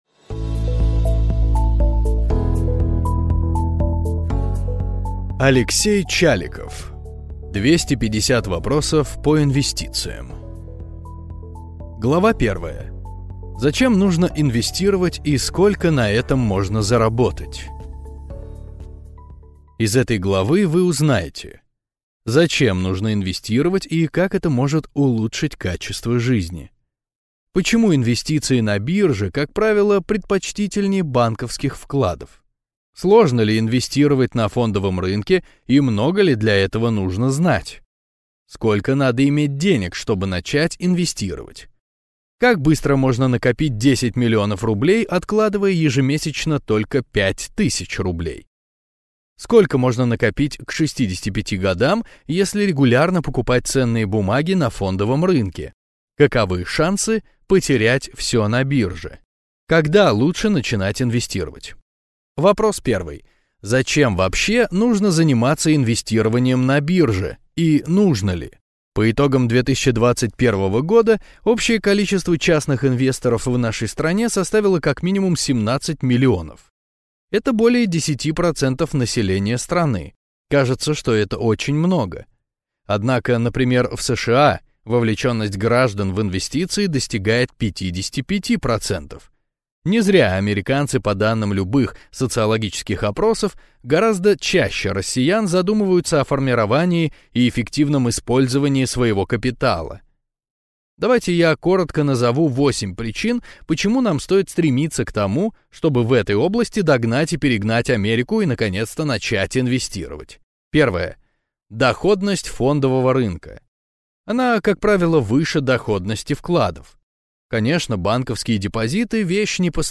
Аудиокнига 250 вопросов по инвестициям | Библиотека аудиокниг
Прослушать и бесплатно скачать фрагмент аудиокниги